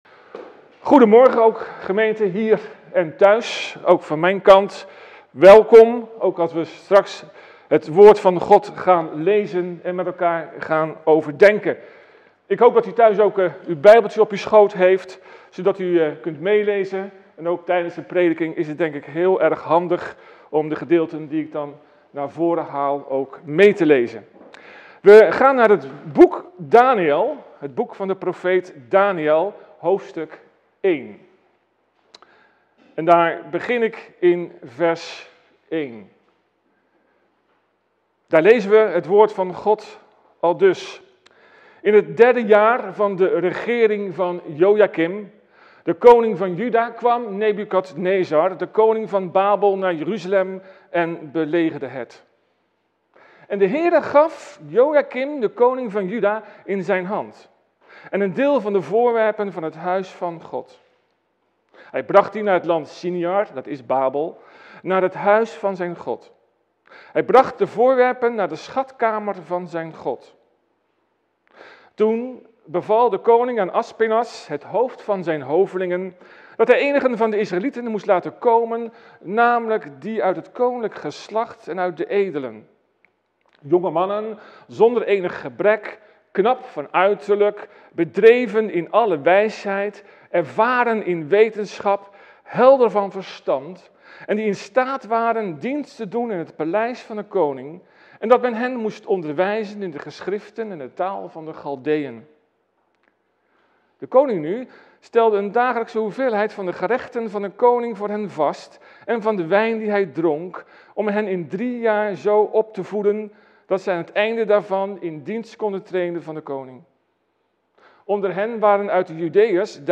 Preek-7-Durf-een-Daniel-te-zijn.mp3